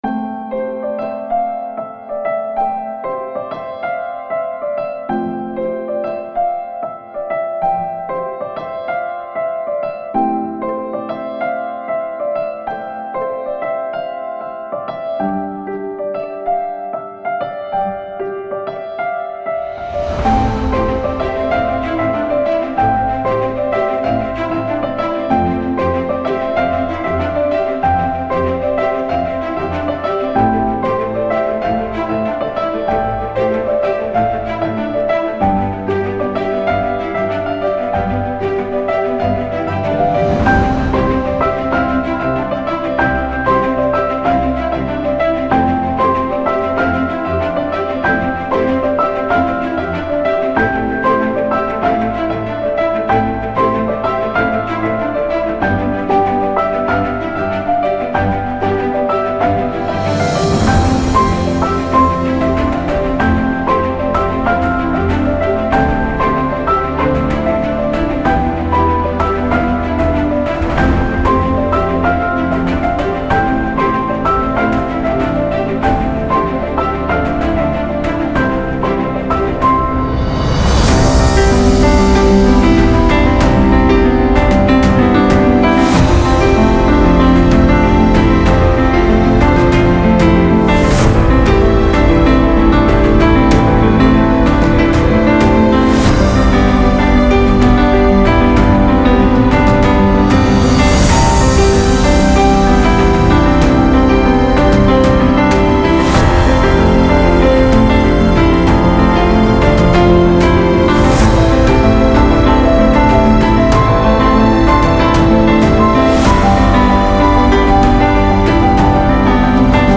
موسیقی بی کلام الهام‌بخش پیانو تدوین فیلم و عکس سینمایی